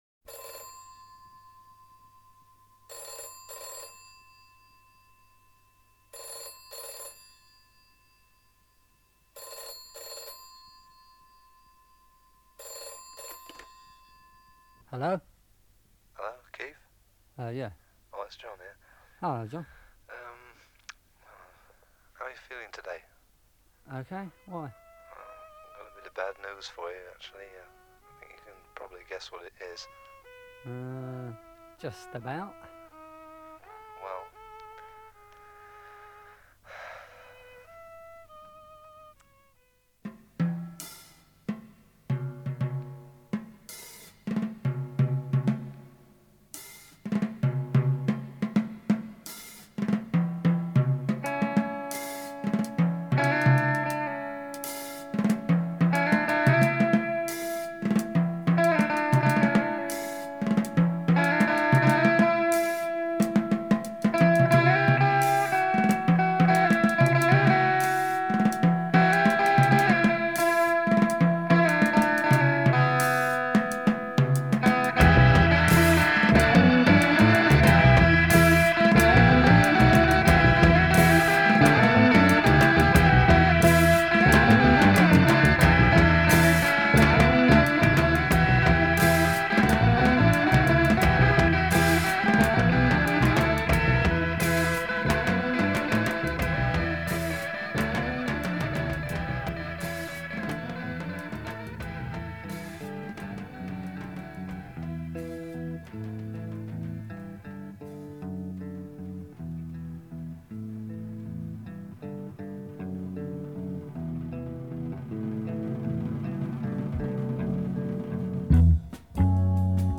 British blues rock